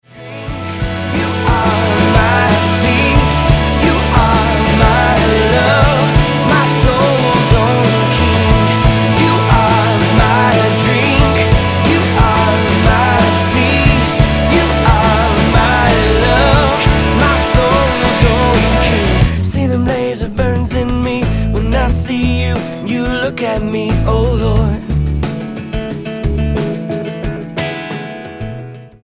STYLE: Pop
slow tempo praise songs